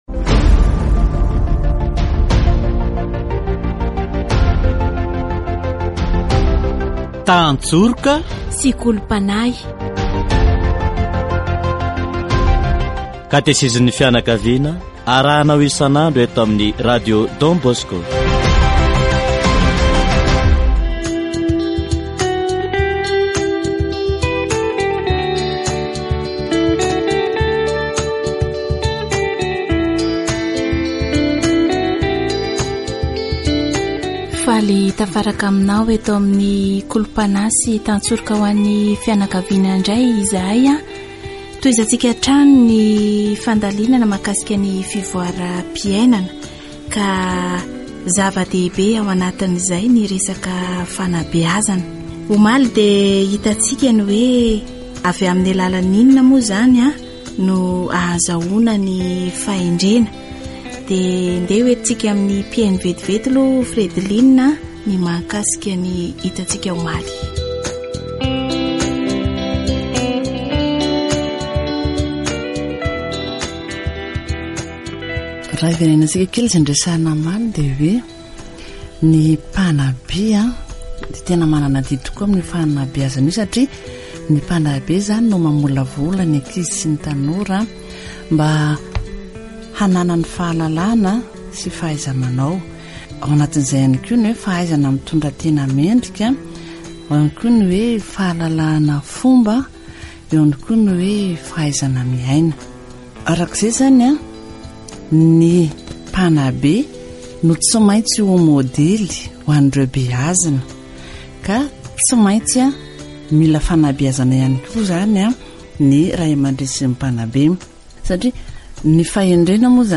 Parents themselves need education first of all, because wisdom comes from observing facts and experiences, and this is what lasts and stays with children. Catechesis on the Church